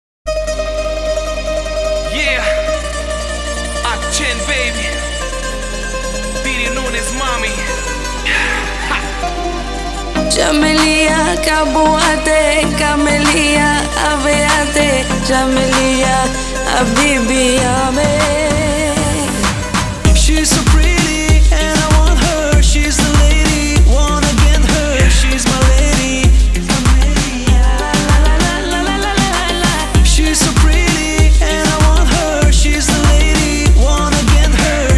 Жанр: Танцевальные / Поп